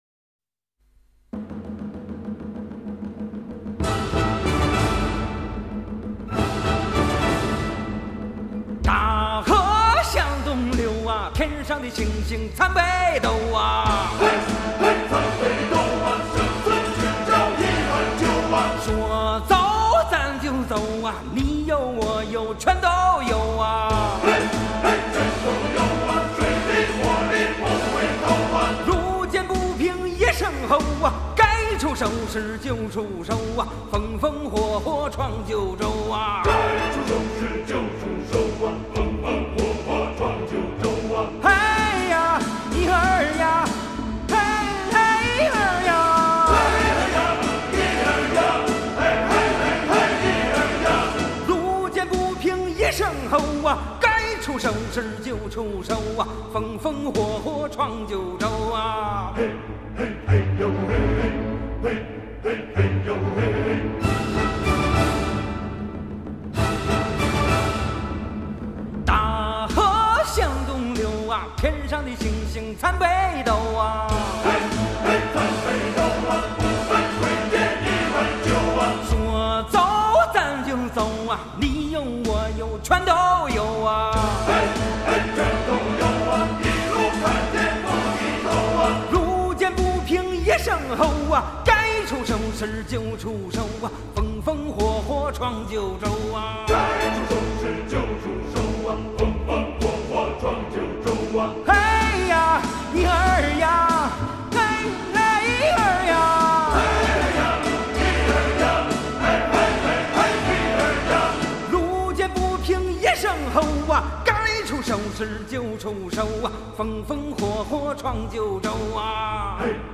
音乐风格：原声带、影视音乐